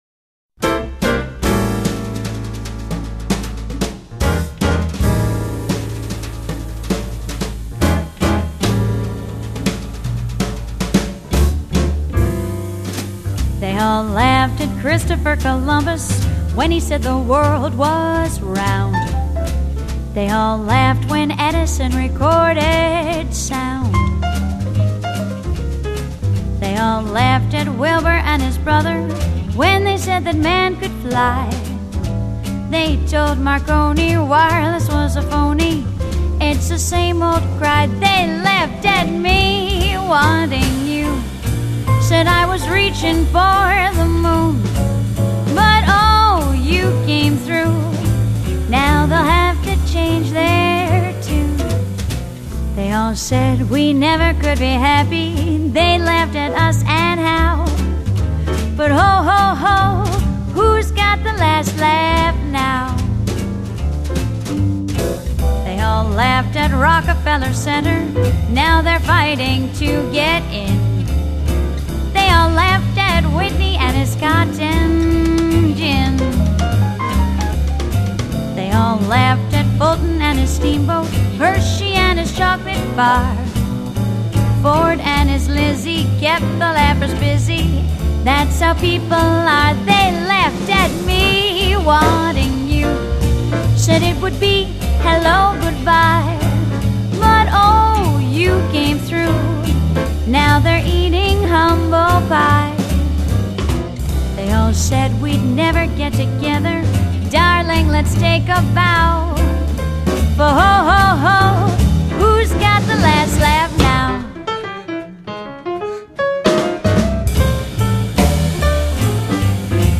爵士乐
以其甜美性感的聲音魅力、柔媚優雅的醉人嗓子